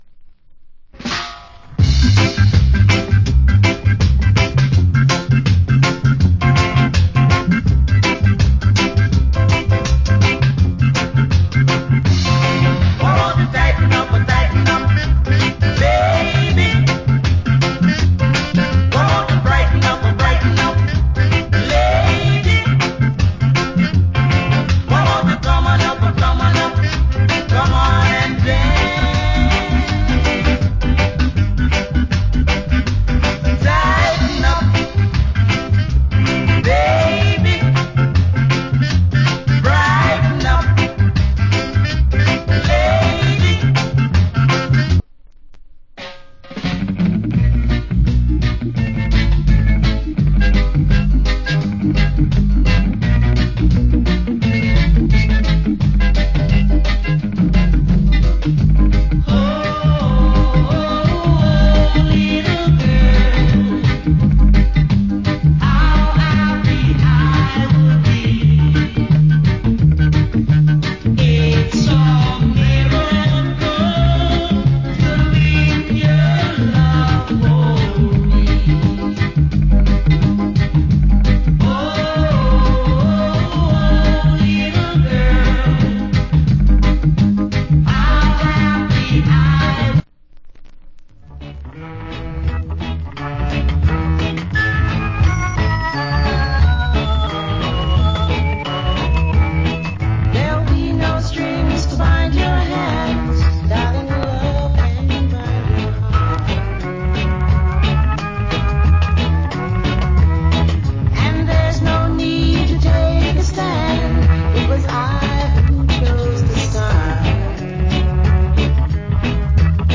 Late 60's Great Reggae.